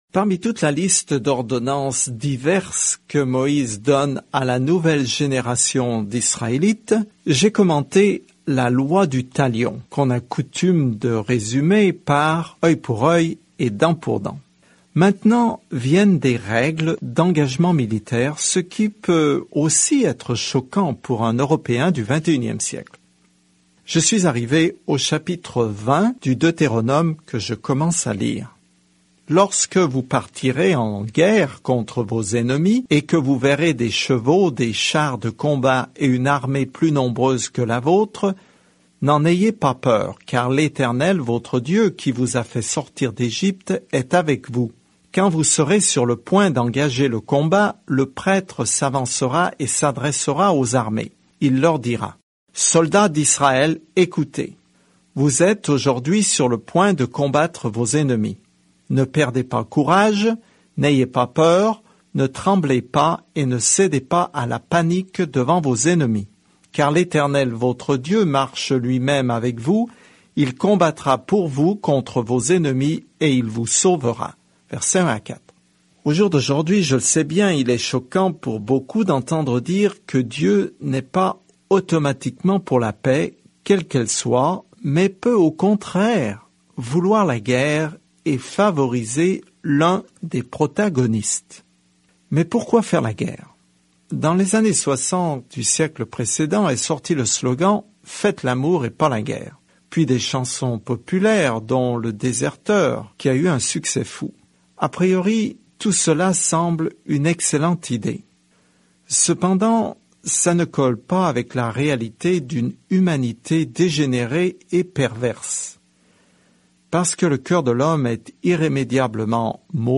Écritures Deutéronome 20 Deutéronome 21 Jour 11 Commencer ce plan Jour 13 À propos de ce plan Le Deutéronome résume la bonne loi de Dieu et enseigne que l’obéissance est notre réponse à son amour. Parcourez quotidiennement le Deutéronome en écoutant l’étude audio et en lisant certains versets de la parole de Dieu.